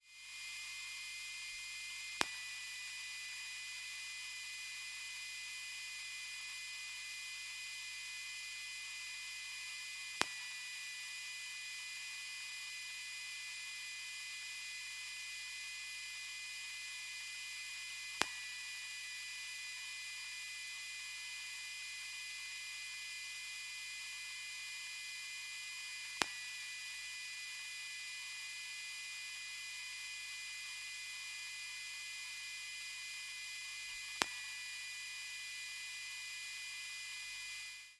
Шум от работы ноутбука